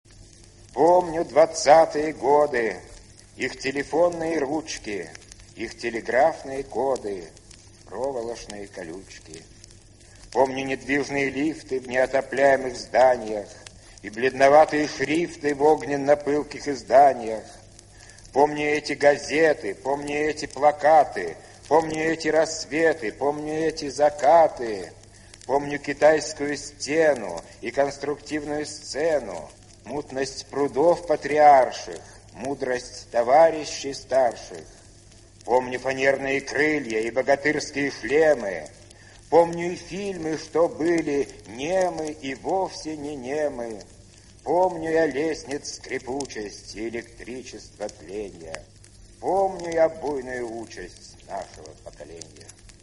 2. «Леонид Мартынов – Двадцатые годы (читает автор)» /
Martynov-Dvadcatye-gody-chitaet-avtor-stih-club-ru.mp3